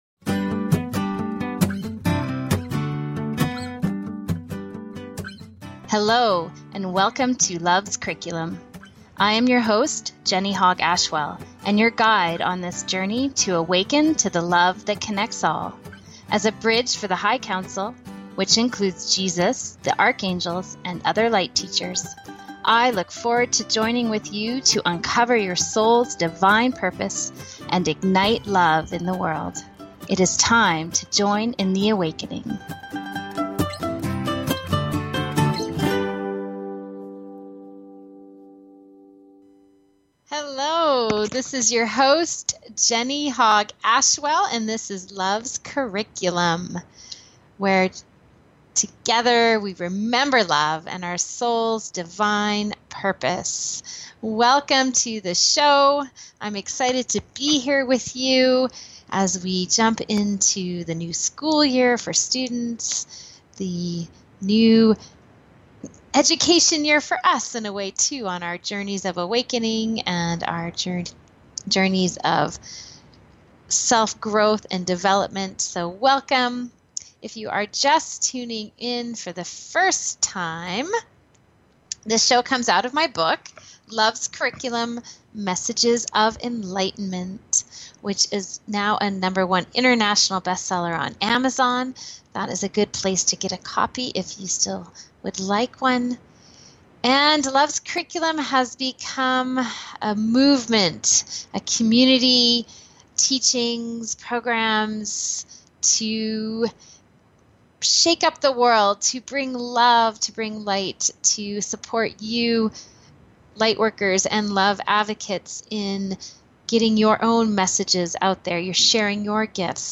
Talk Show Episode, Audio Podcast, Loves Curriculum and Connecting To Home on , show guests , about Connecting To Home, categorized as Health & Lifestyle,Energy Healing,Kids & Family,Paranormal,Philosophy,Psychology,Self Help,Spiritual,Medium & Channeling